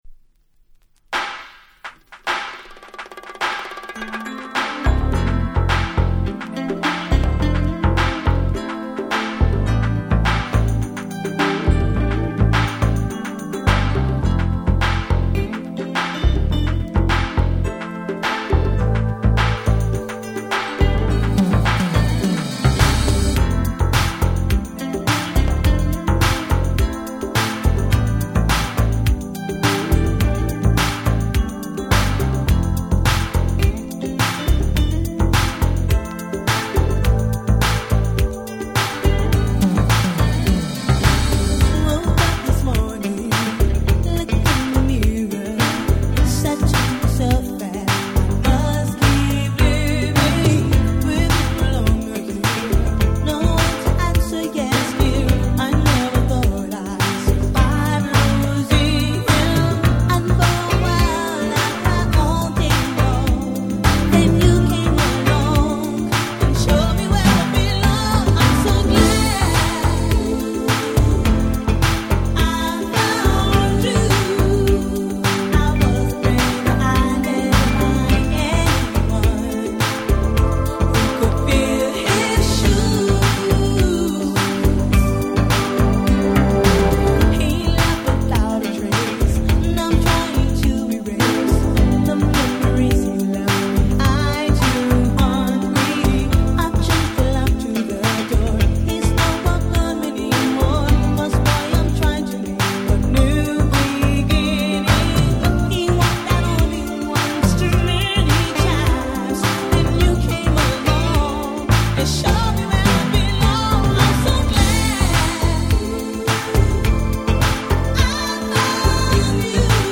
88' Nice UK R&B !!
硬質なBeatに切ないメロディ、、、ってとにかく説明するより是非聴いて頂きたい！！